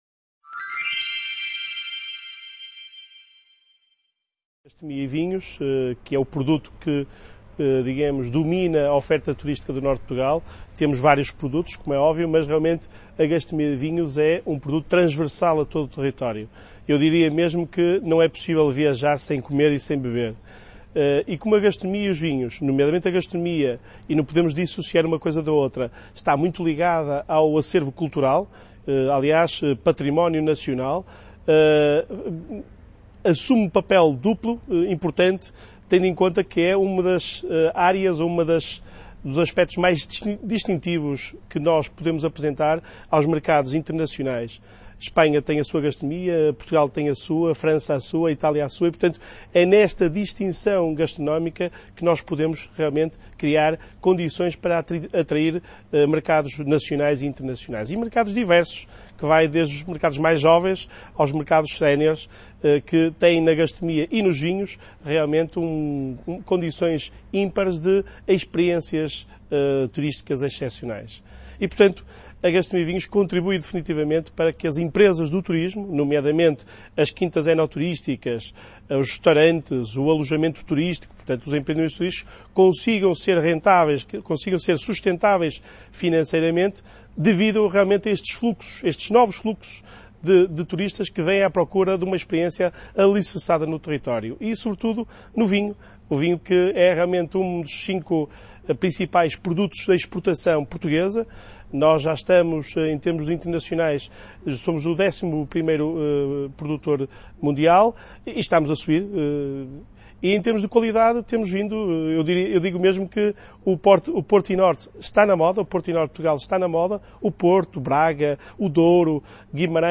Entrevista
C.A. Ponferrada - II Congreso Territorial del Noroeste Ibérico